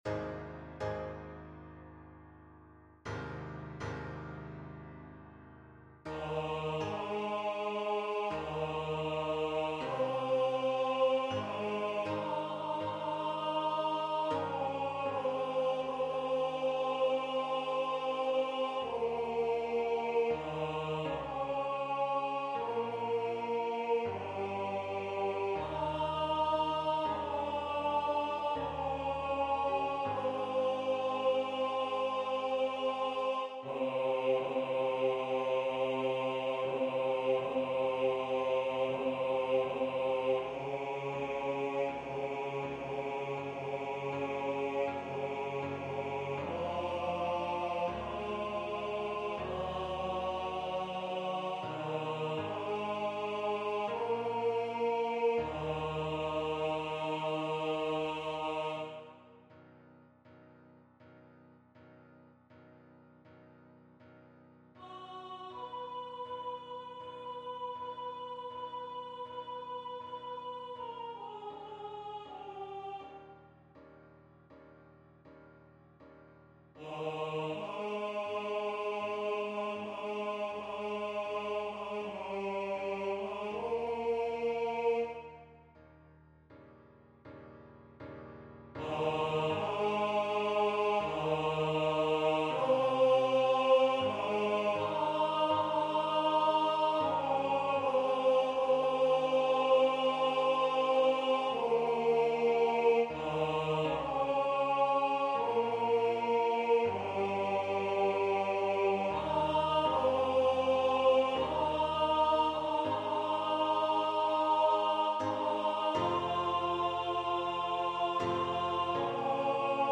PraiseOfGod_tenor.mp3